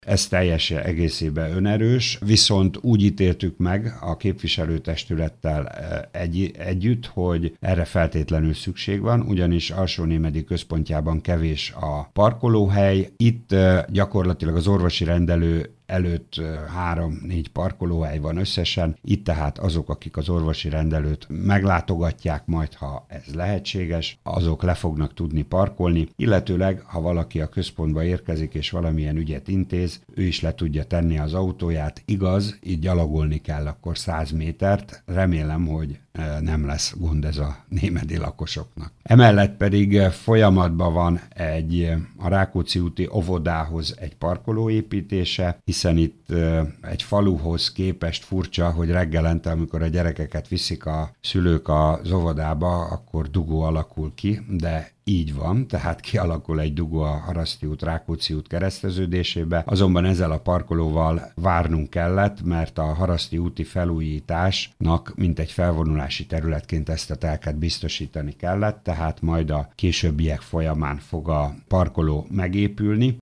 Dr. Tüske Zoltán polgármestert hallják.